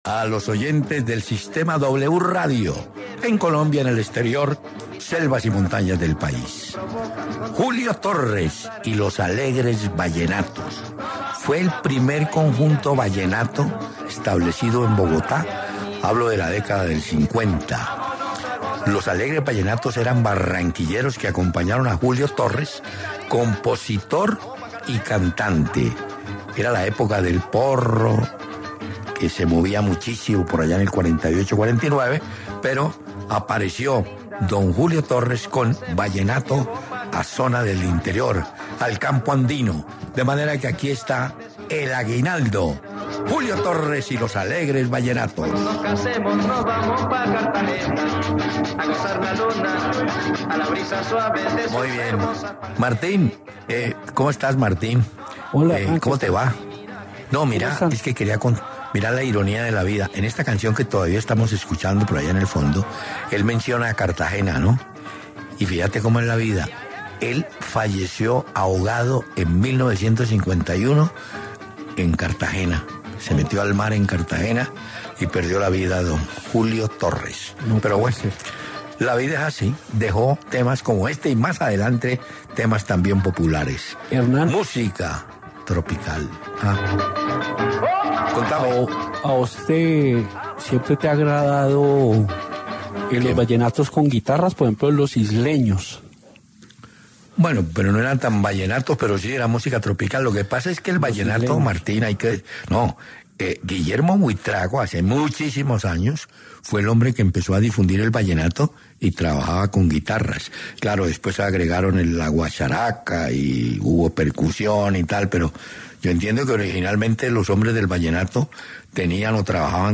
Hernán Peláez y Martín de Francisco conversaron sobre la victoria de Nacional contra América.